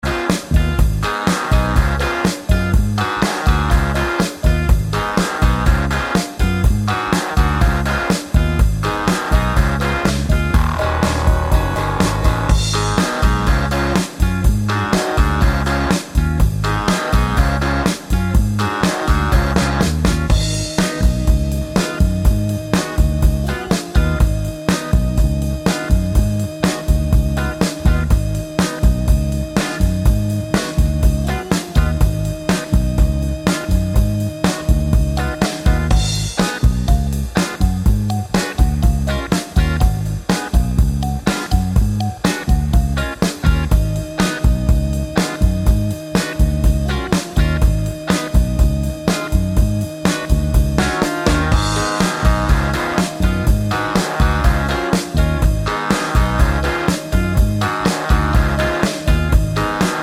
Minus Main Guitars Rock 3:14 Buy £1.50